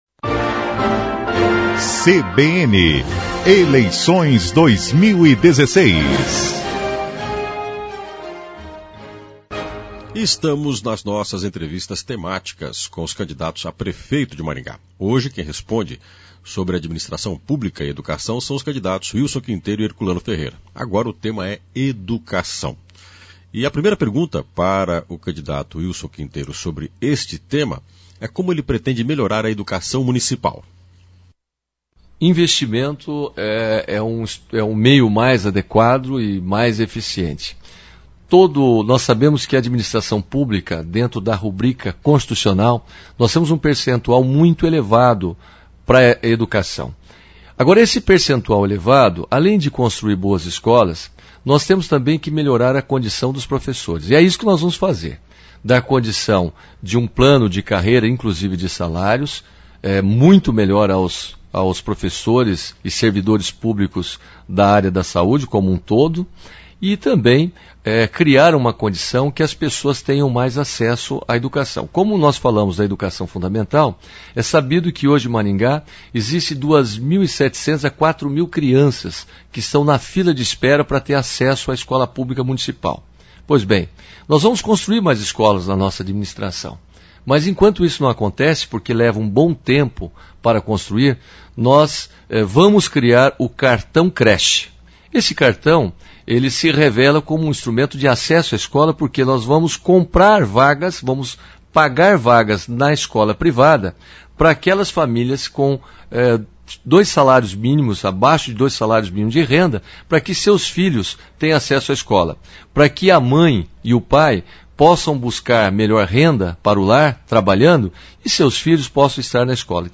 A série de entrevistas temáticas com os candidatos a prefeito de Maringá vai até o dia 29 de setembro. Cada dia dois candidatos escolhidos por sorteio respondem sobre dois temas.